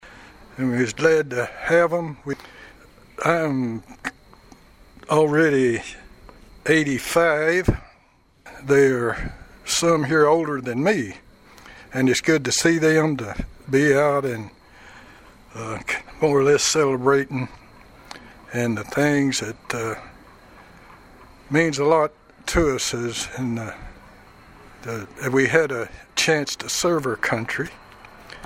The Pleasant Grove Baptist Church located on Hopkinsville Road in Princeton recognized Veterans on Saturday night with a fish meal prepared by the church members.